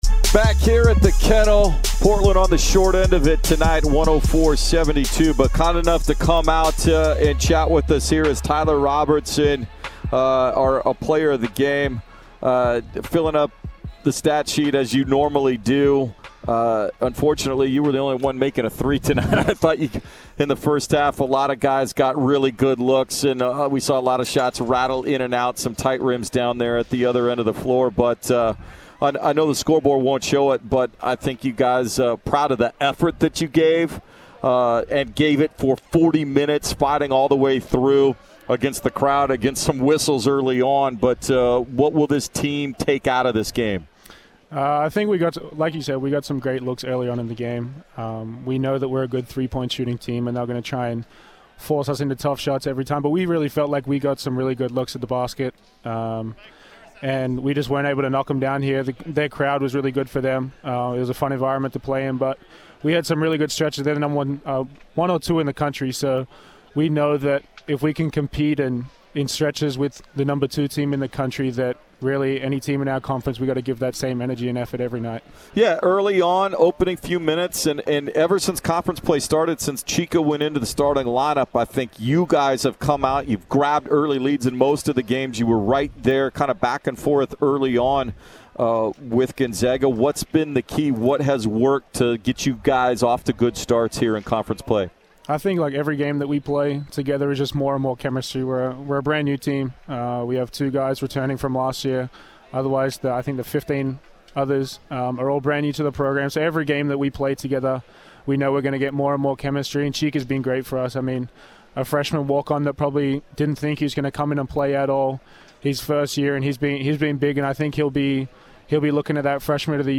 Postgame Interviews vs. Gonzaga